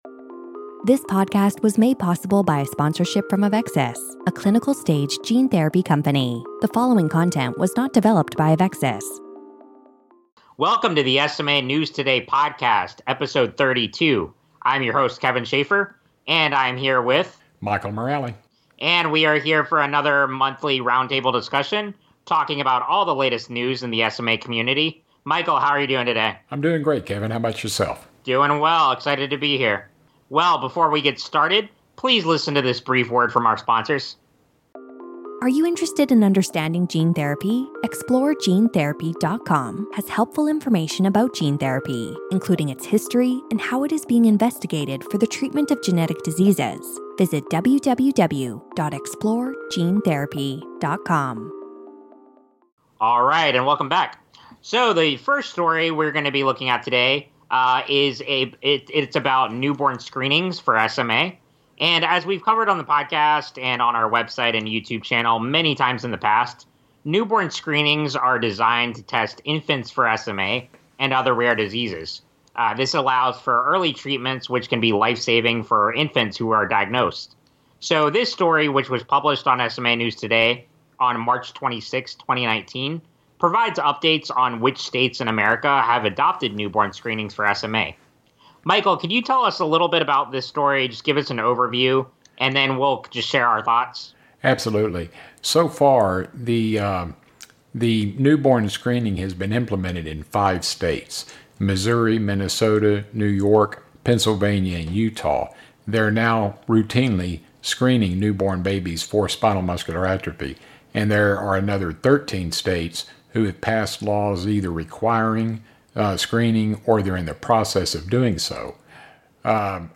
#32 - Roundtable Discussion - Discussion for April 2019